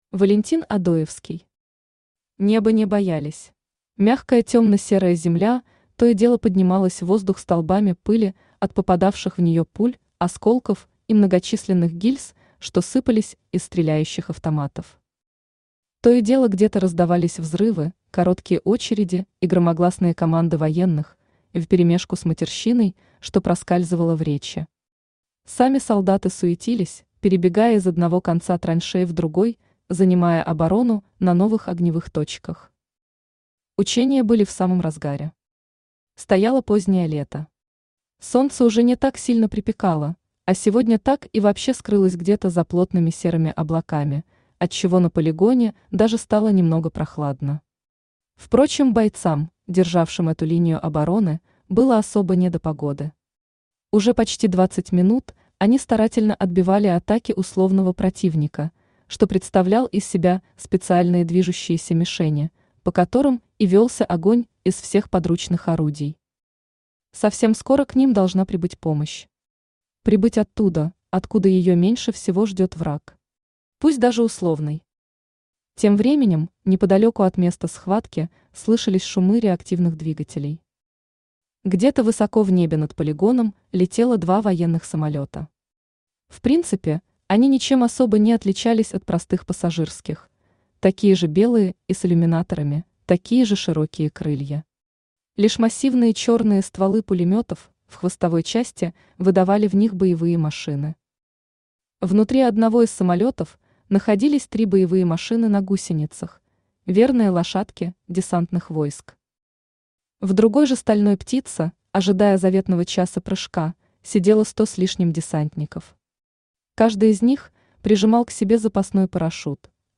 Аудиокнига Неба не боялись | Библиотека аудиокниг
Aудиокнига Неба не боялись Автор Валентин Одоевский Читает аудиокнигу Авточтец ЛитРес.